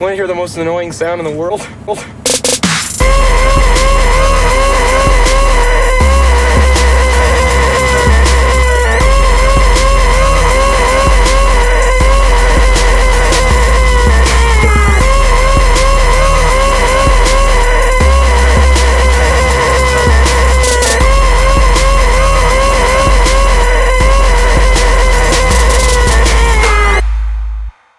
Jim Carrey is the best synth lead.
The most annoying sound in the world
trap and bass
EDM
Trap Kit
Dubstep (Musical Genre)